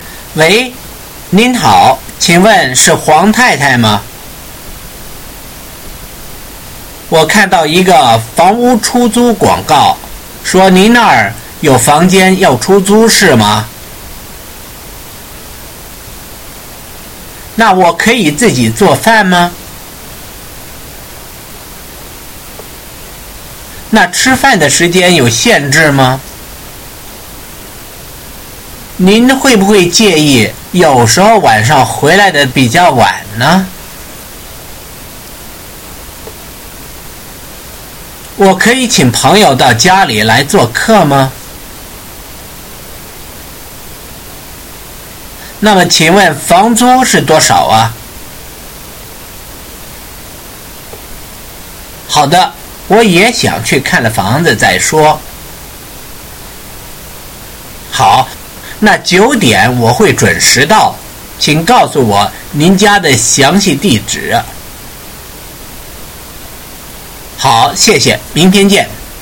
Conversation on the phone | 打 电 话